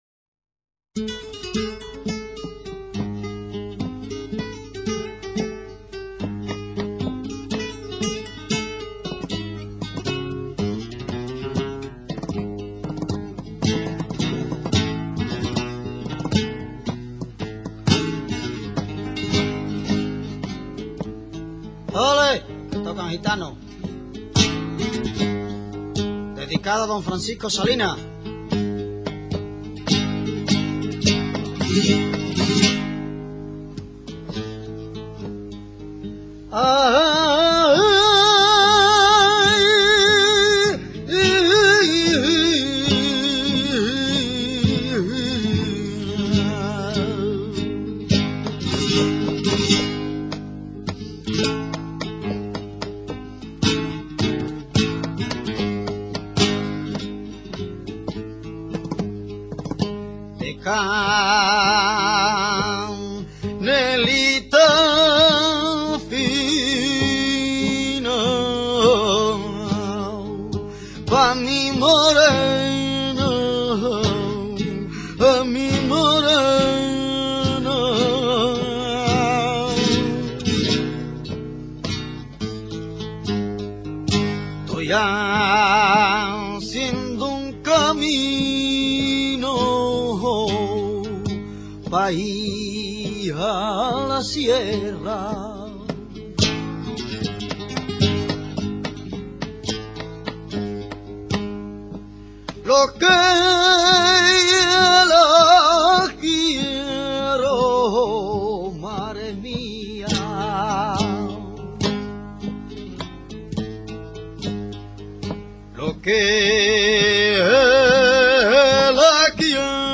Actualmente se acompa�a de guitarra.
Escuchar una Liviana
liviana.mp3